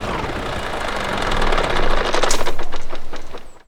Index of /server/sound/vehicles/lwcars/truck_daf_xfeuro6
stop.wav